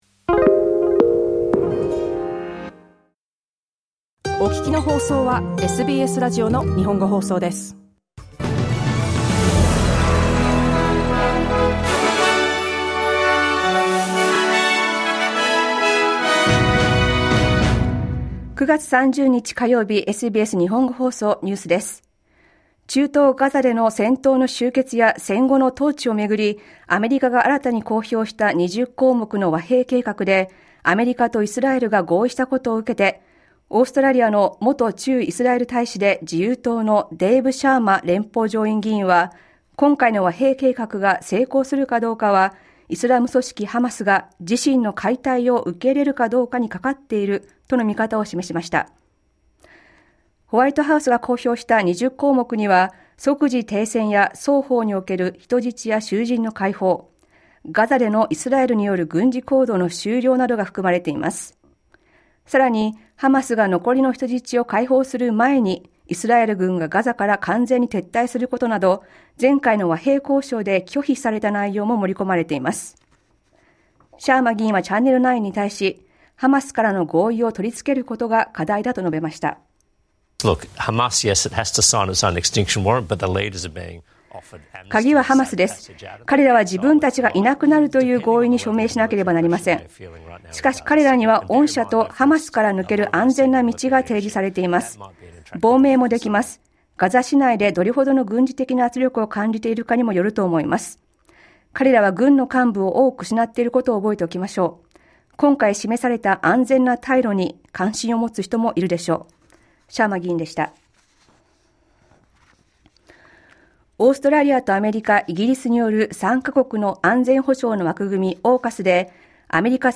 SBS日本語放送ニュース9月30日火曜日